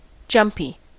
jumpy: Wikimedia Commons US English Pronunciations
En-us-jumpy.WAV